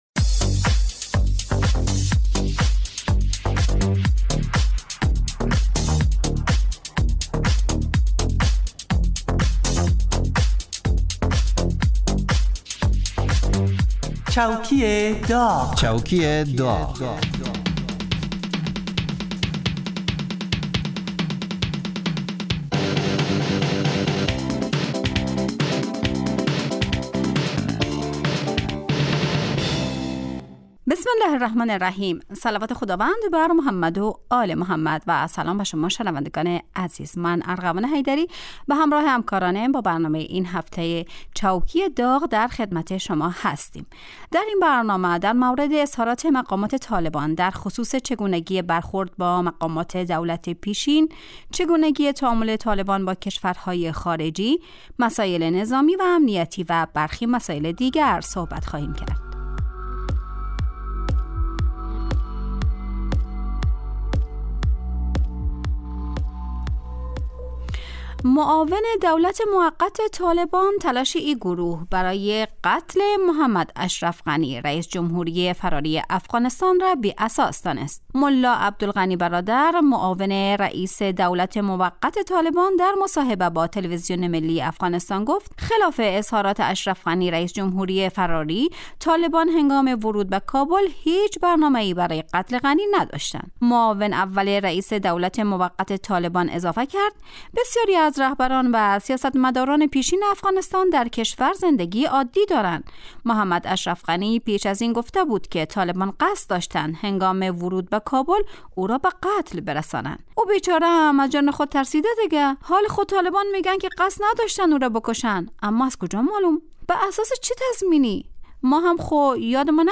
برنامه چوکی داغ به مدت 30 دقیقه روز جمعه در ساعت 2:30 (به وقت افغانستان) پخش می شود. این برنامه با نیم نگاه طنز به مرور و بررسی اخبار و رویدادهای مهم مربوط به دولت حاکم در افغانستان می پردازد.